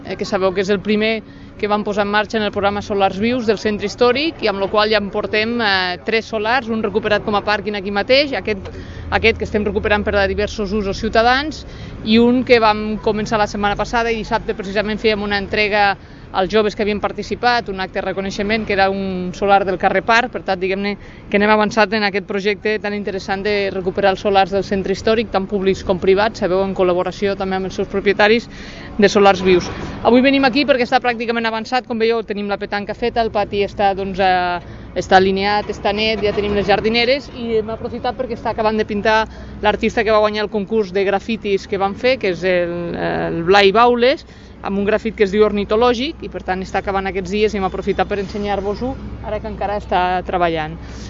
arxiu-de-so-de-lalcaldessa-accidental-marta-camps